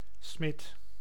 Ääntäminen
IPA : /smɪθ/